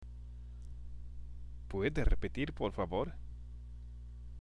（プエデ　レペティール　ポルファボール？）